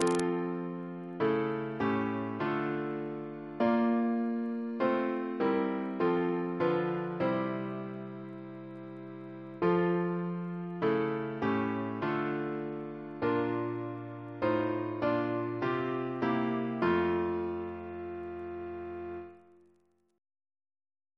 Double chant in F Composer: Edward Hodges (1796-1867) Reference psalters: H1982: S276